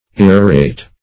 Irrorate \Ir"ro*rate\, v. t. [imp. & p. p. Irrorated; p. pr. &